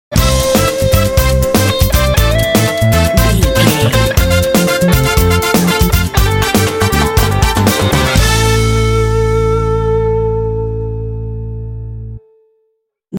Uplifting
Aeolian/Minor
cool
smooth
lively
energetic
driving
percussion
drums
electric guitar
bass guitar
brass
latin